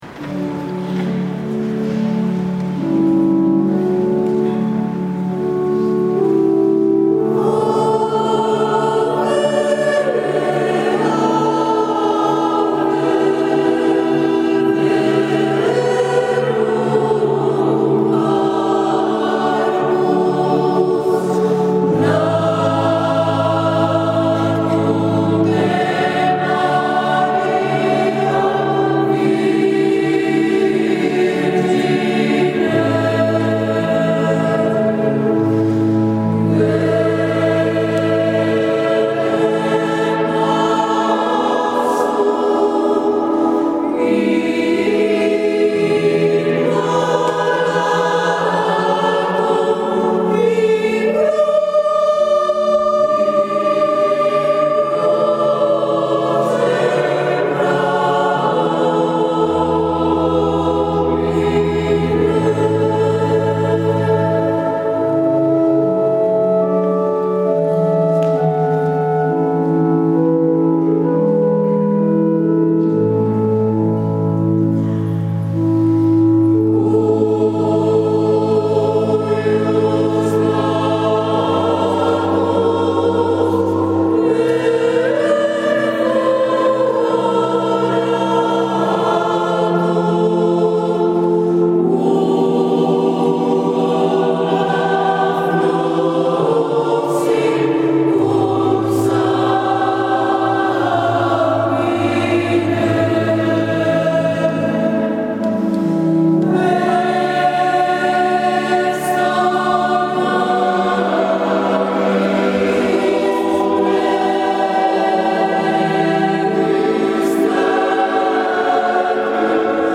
Domenica 22 gennaio 2012 la corale ha animato la S. Messa nel Duomo di Bergamo Alta.